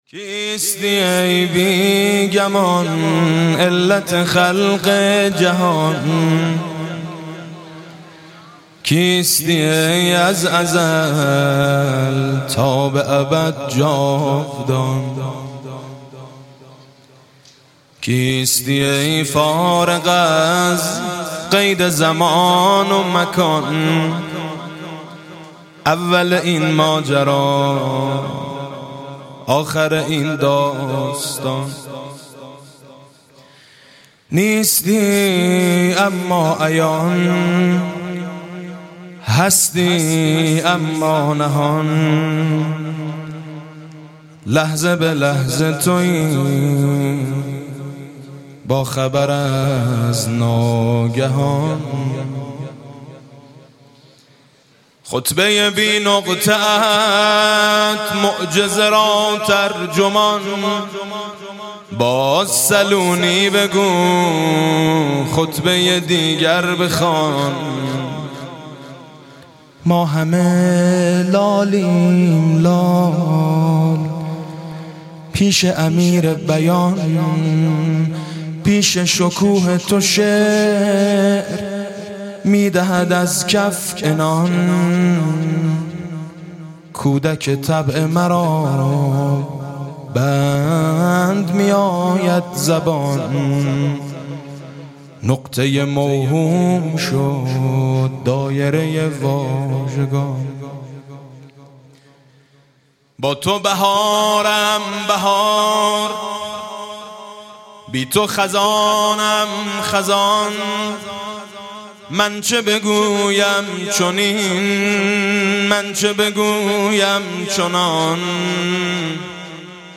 مدح: کیستی ای بی گمان علت خلق جهان